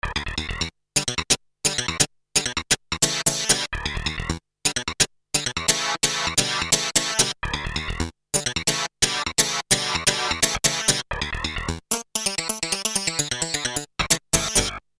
Double set of cards for both synthesizer Roland JD-800 or module JD-990.
A-13. Anvil Clav
2-anvilclav.mp3